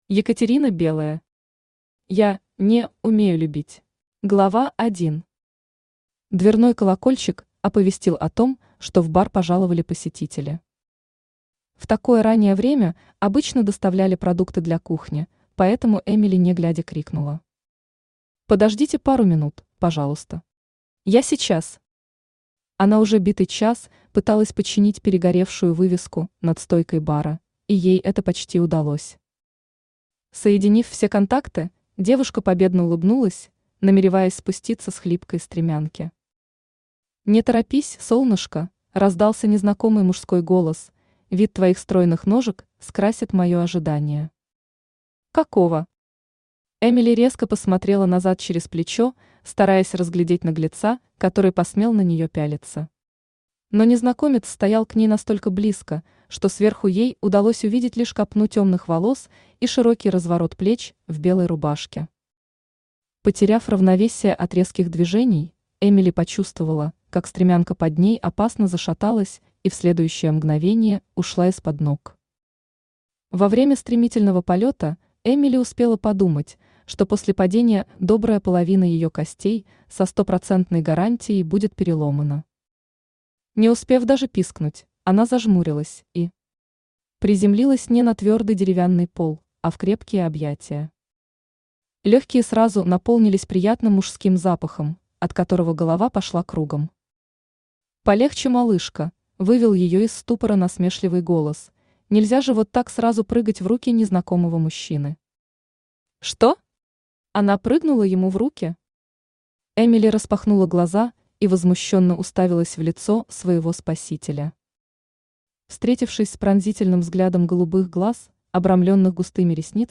Аудиокнига Я (не) умею любить | Библиотека аудиокниг
Aудиокнига Я (не) умею любить Автор Екатерина Белая Читает аудиокнигу Авточтец ЛитРес.